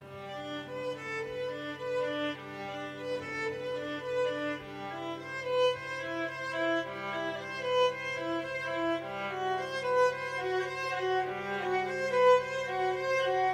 Audición de diferentes sonidos de la familia de cuerda frotada.
Es un poquito más grave que el violín...
Viola